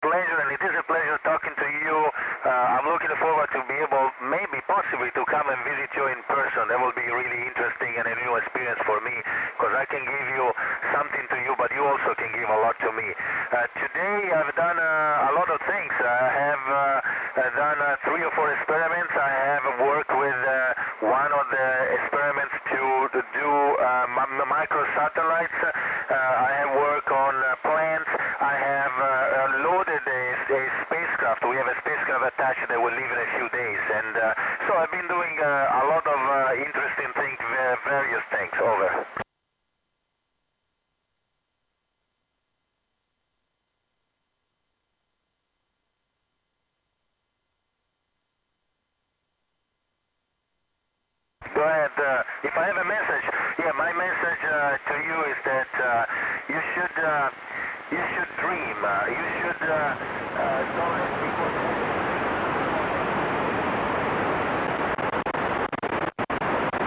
iss voice record